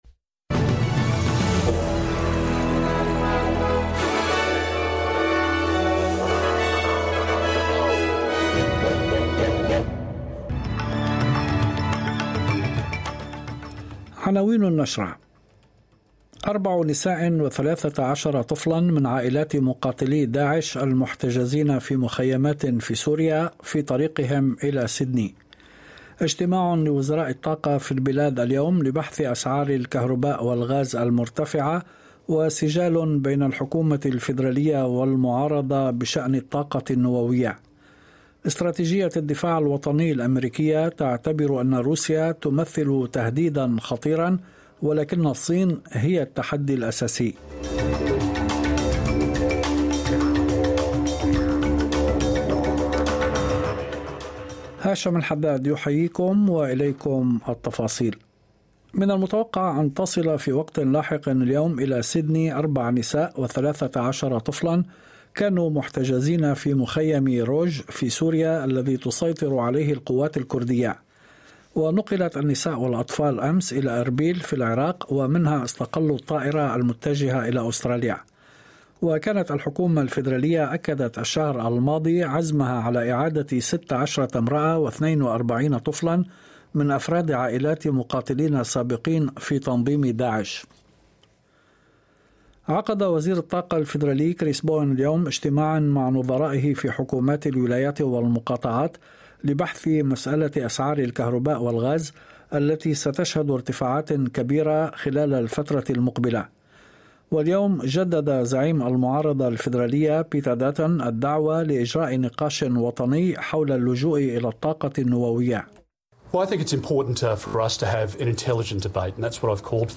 نشرة أخبار المساء 28/10/2022